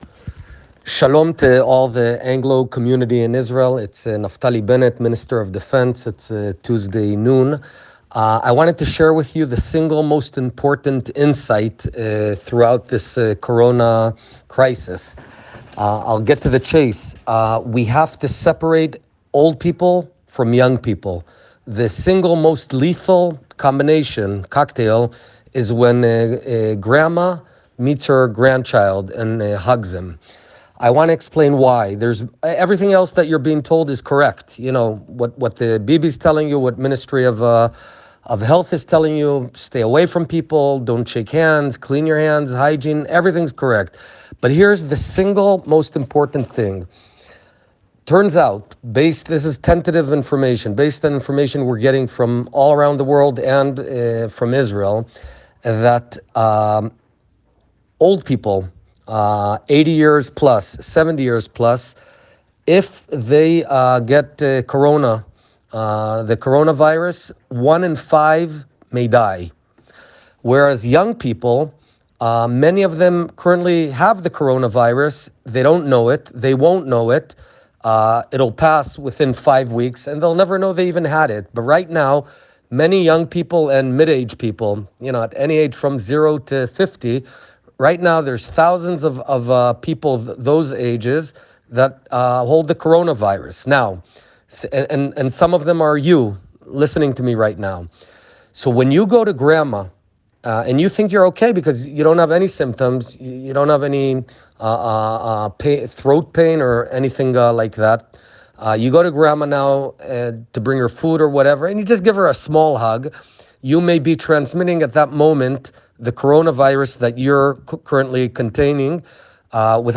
Please listen to the attached voice-message in English from Israel Defense Minister Naftali Bennett. He explains how deadly the Coronavirus is for the elderly – based on new information available from all around the world.